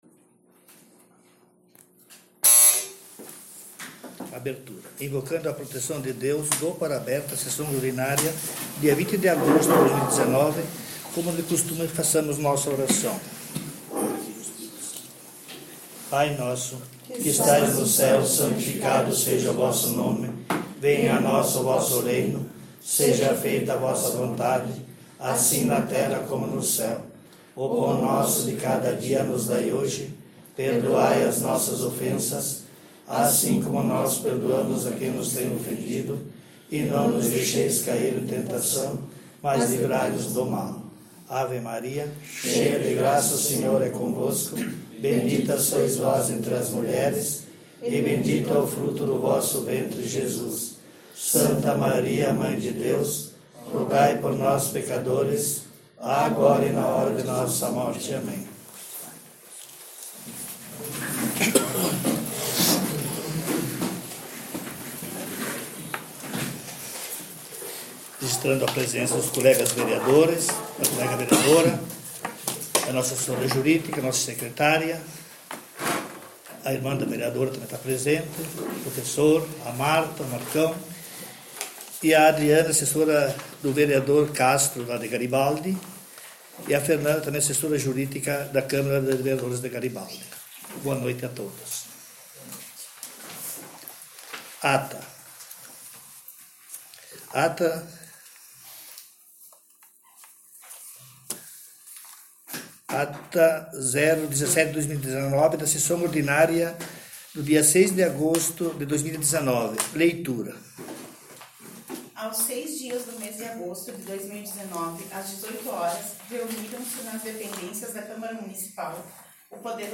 Sessão Ordinária dia 20/08/19